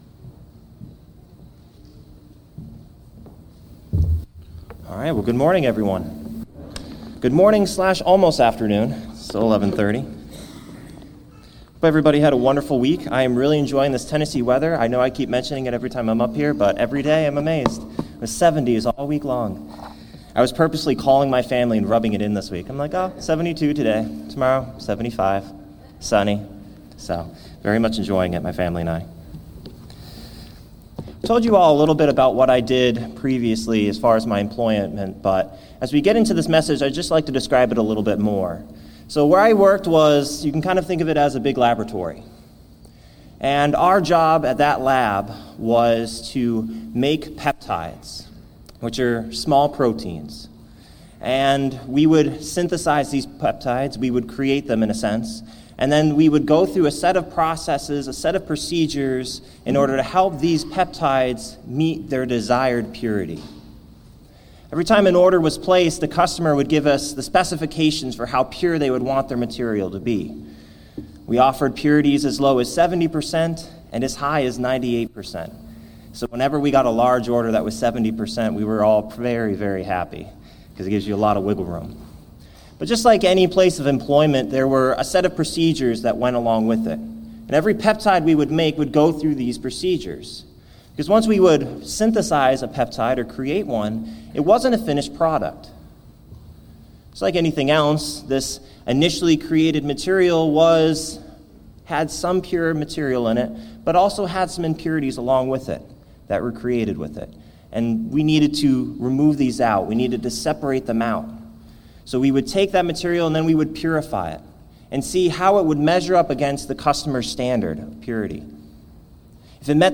Sermons
Given in Murfreesboro, TN